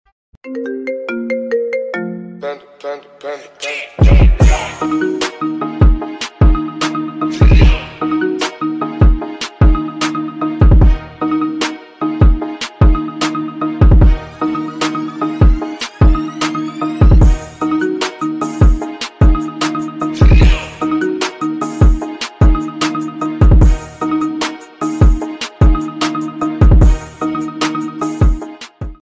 SONIDO DE GATO PARA WHATSAPP es un Tono para tu CELULAR